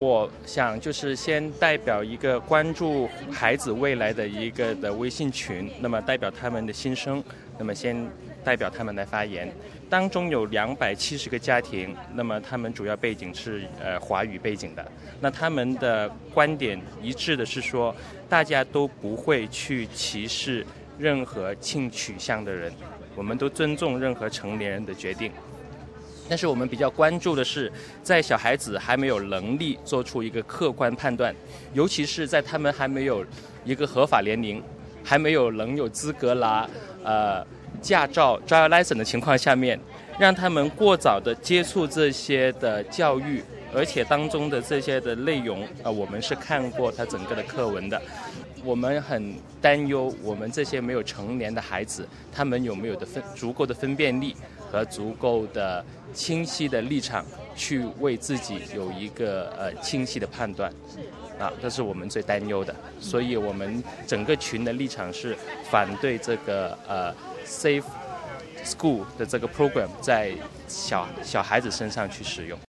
SBS电台普通话团队在现场对候选人和社区民众进行了采访。